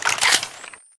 Datei:Wpn laserrcw reloadpt2.ogg